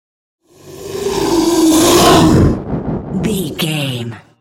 Whoosh large creature
Sound Effects
Atonal
ominous
eerie
roar